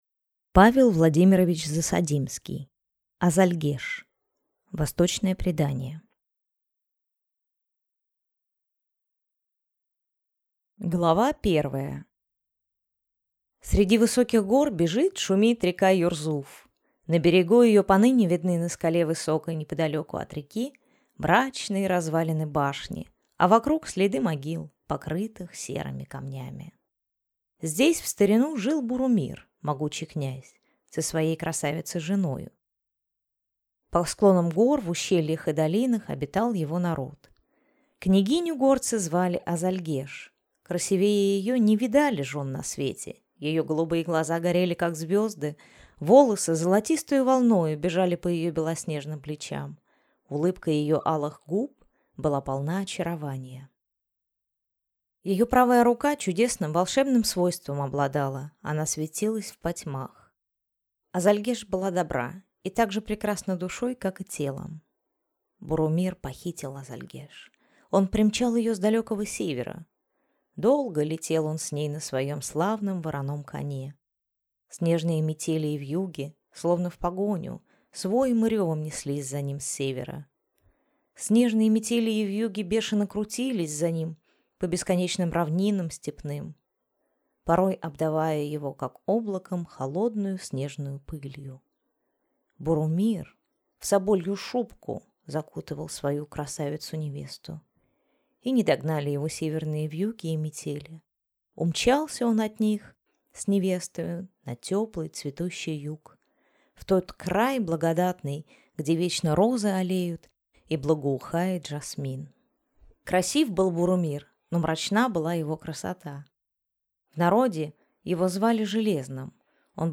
Аудиокнига Азальгеш | Библиотека аудиокниг